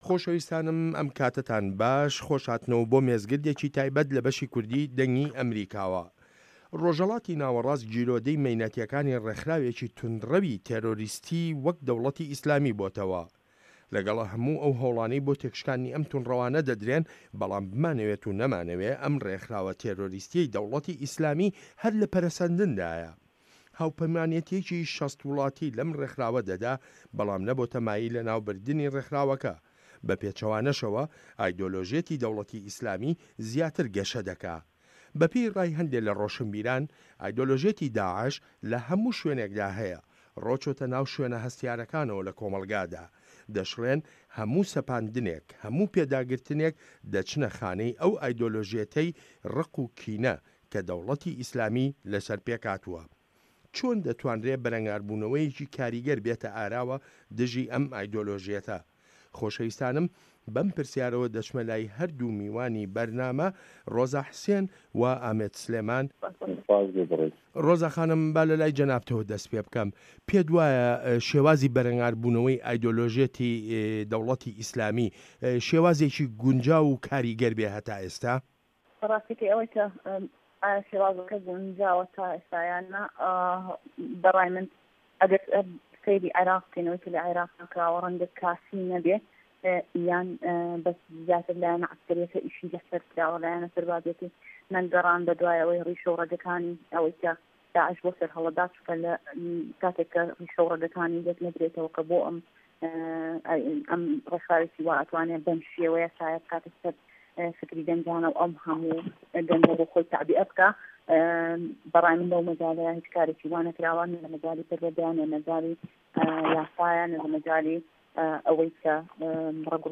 مێزگرد: به‌ره‌نگاربوونه‌وه‌ی ده‌وڵه‌تی ئیسلامی